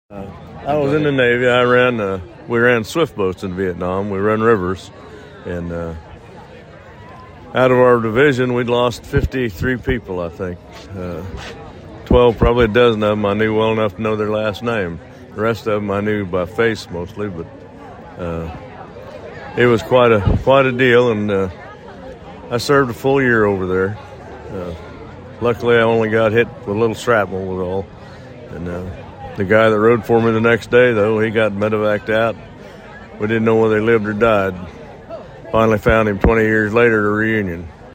On a beautiful Monday morning, the annual Salina Veterans Day Ceremony was held at the Salina-Saline County War Memorial  in Sunset Park.